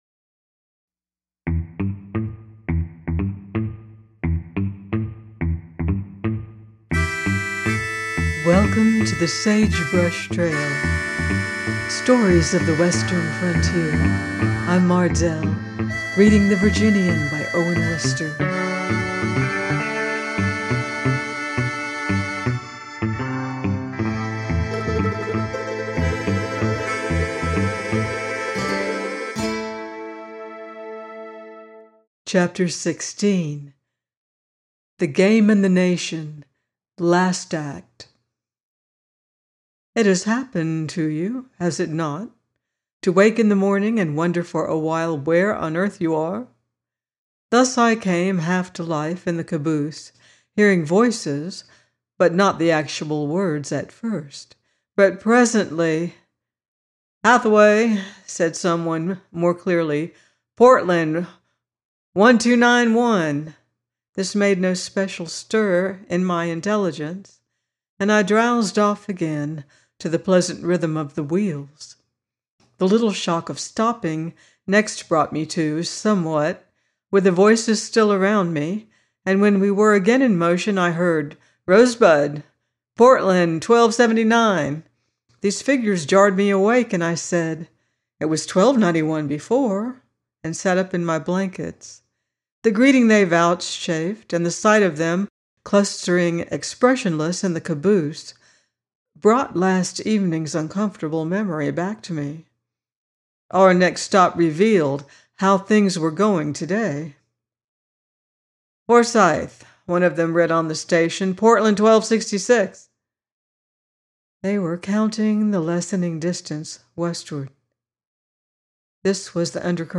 The Virginian 16 - by Owen Wister - audiobook